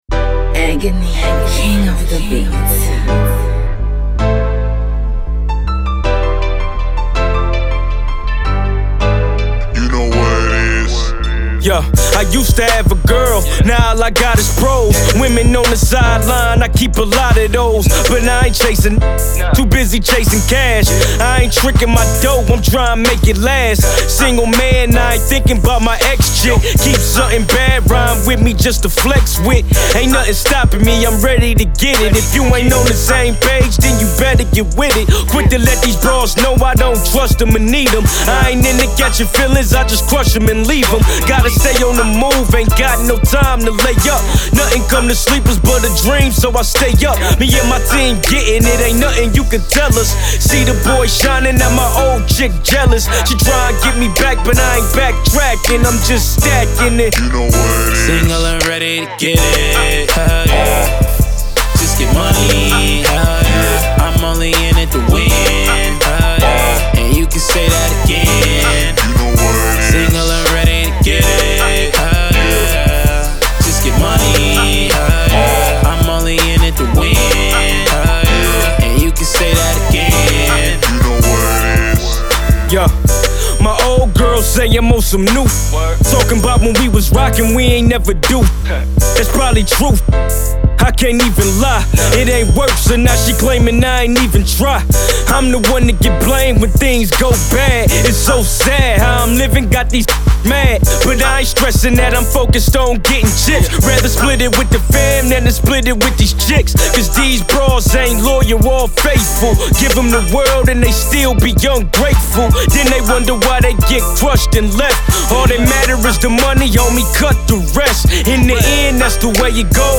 catchy hook